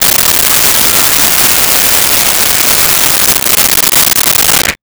Blender On Grate
Blender on Grate.wav